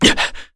Chase-Vox_Landing_kr.wav